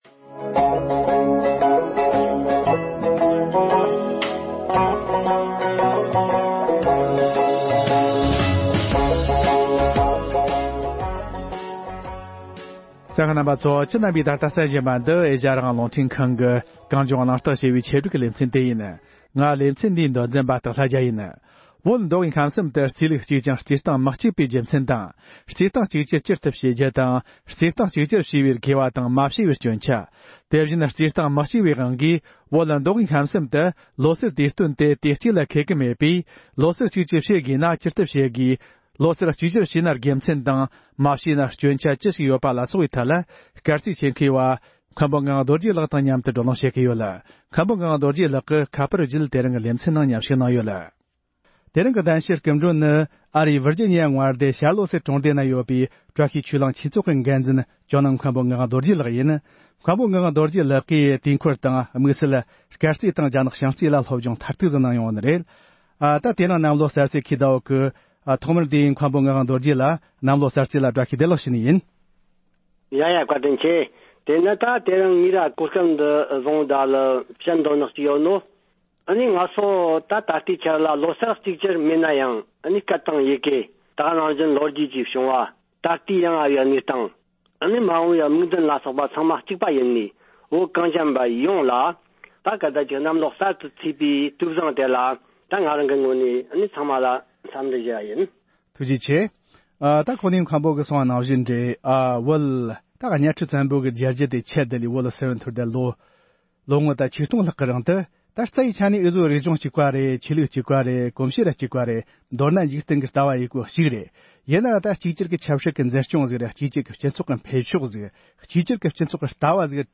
བོད་ནང་ལོ་གསར་གཅིག་གྱུར་བྱེད་དགོས་ན་ཇི་ལྟར་བྱེད་དགོས་པའི་ཐད་བགྲོ་གླེང་།